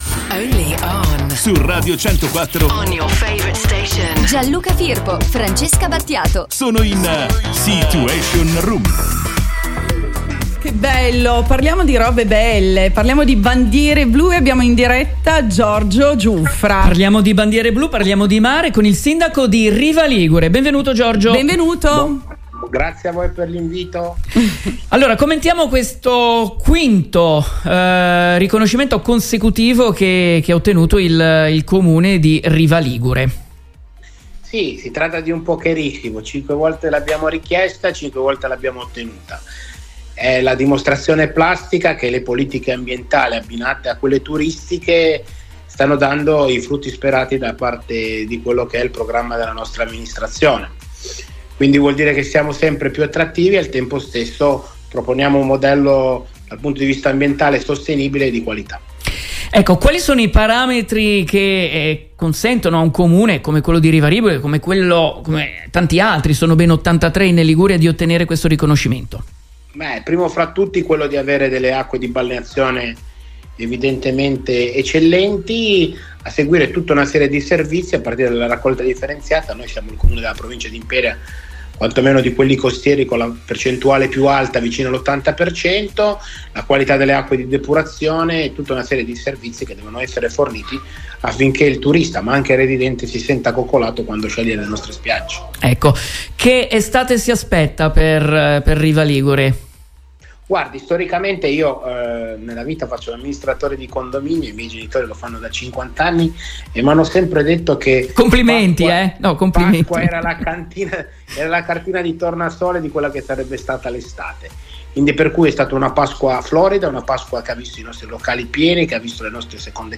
Giorgio Giuffra, sindaco di Riva Ligure ci parla di bandiere blu